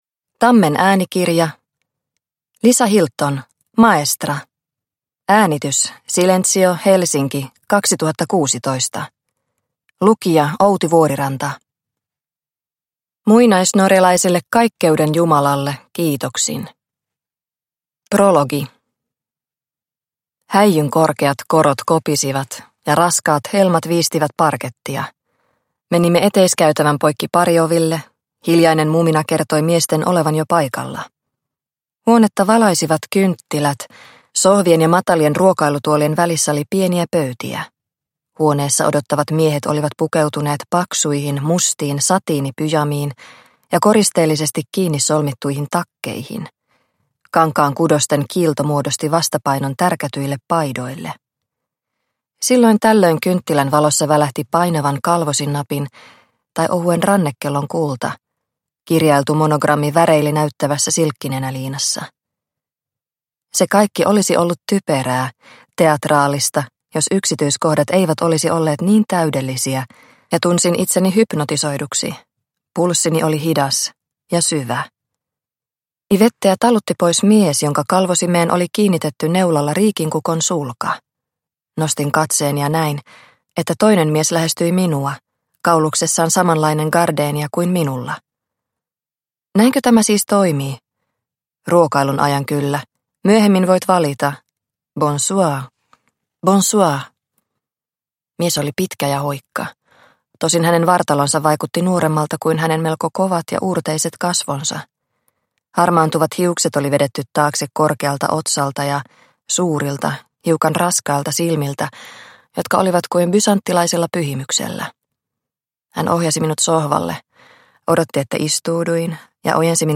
Maestra – Ljudbok – Laddas ner